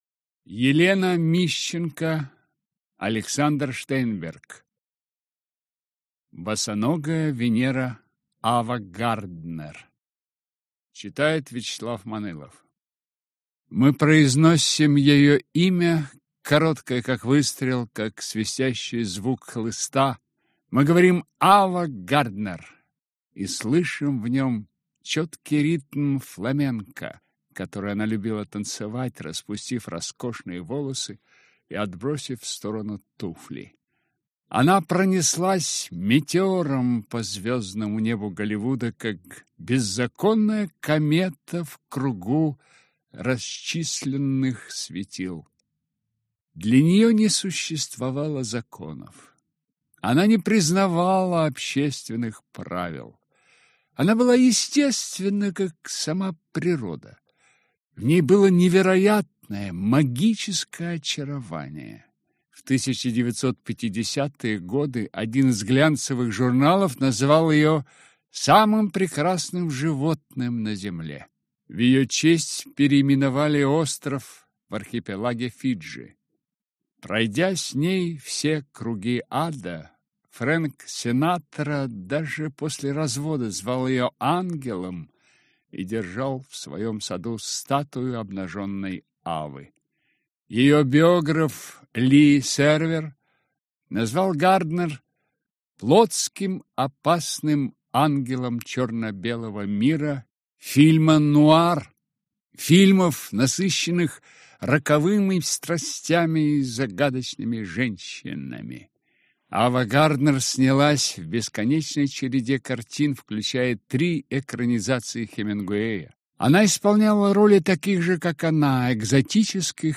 Аудиокнига Босоногая Венера. Ава Гарднер | Библиотека аудиокниг